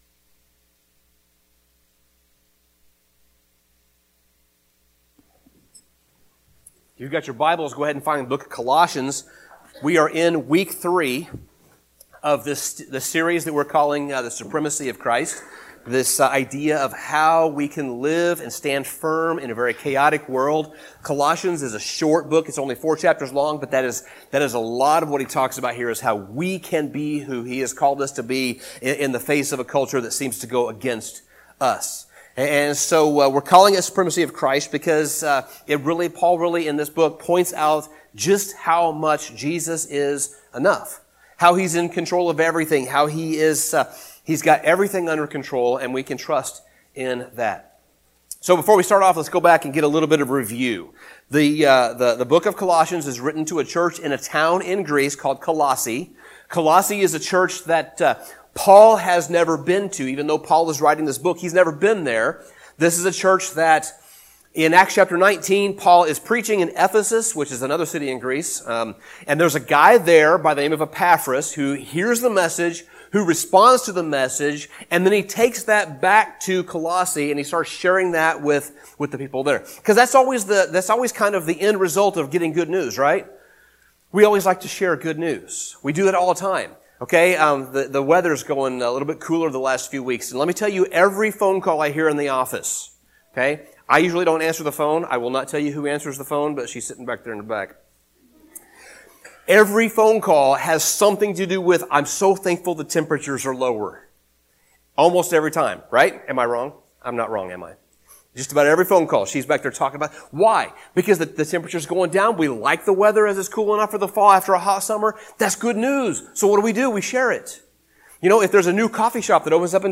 Sermon Summary Once Paul gives his clear statement on the supremacy of Jesus, which we looked at last week, he moves to the logical and natural response to who Jesus is: spiritual maturity.